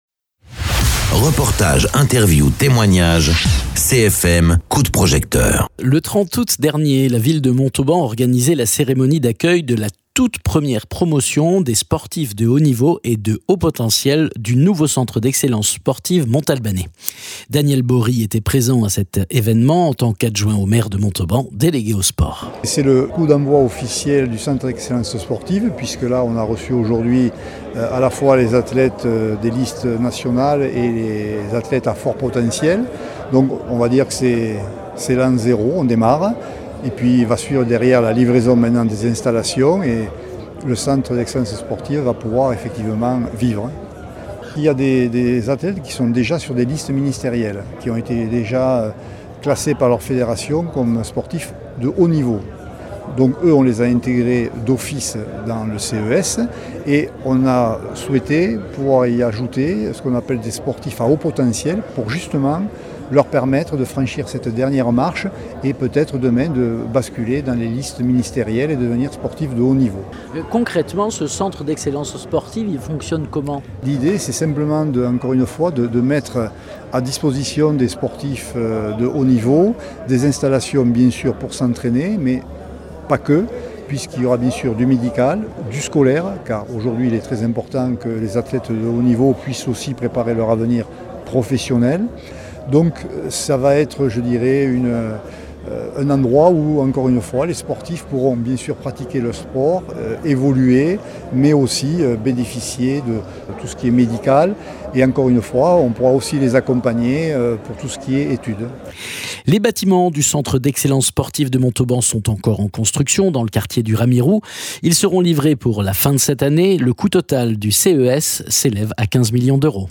Interviews
Daniel Bory était présent à cet événement en tant qu’adjoint au maire de Montauban délégué aux sports.